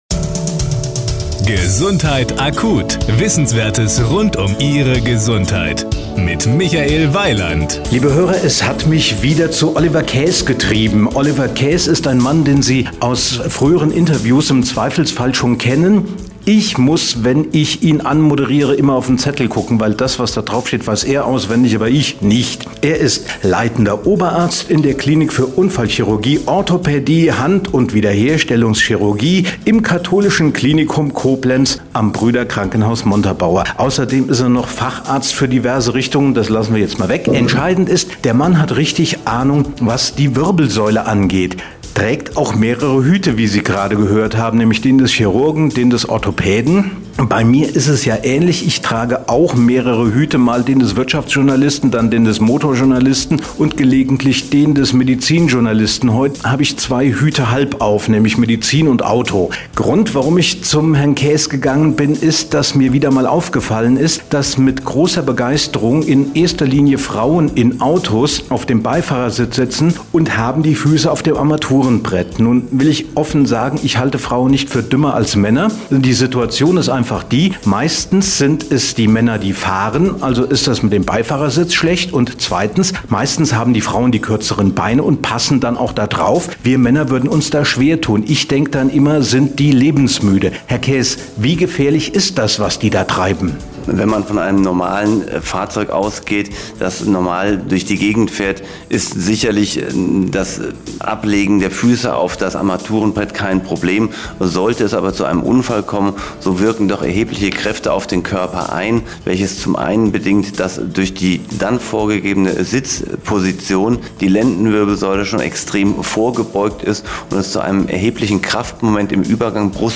Sie sind hier: Start » Interviews » Interviews 2012